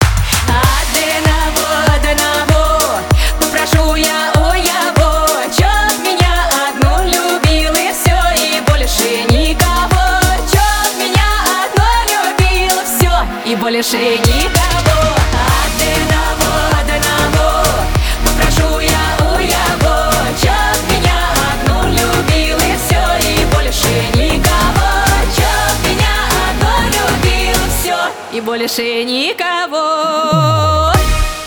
поп
народные